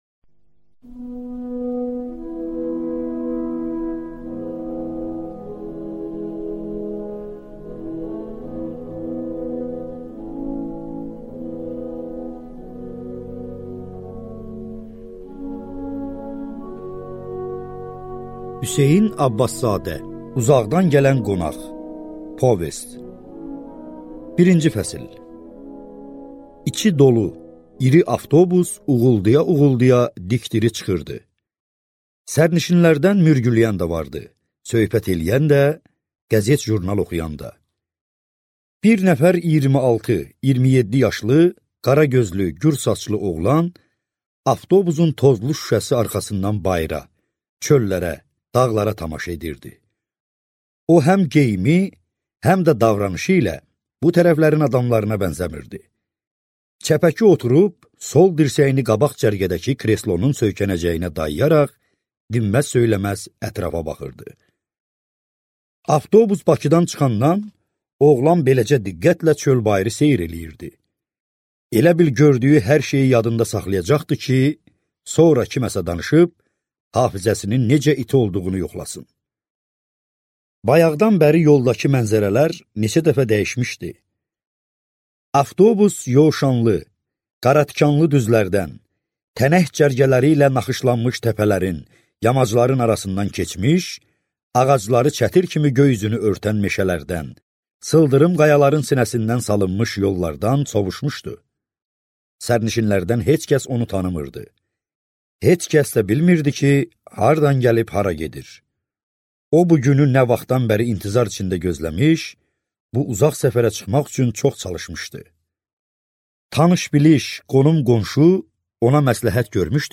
Аудиокнига Uzaqdan gələn qonaq | Библиотека аудиокниг
Прослушать и бесплатно скачать фрагмент аудиокниги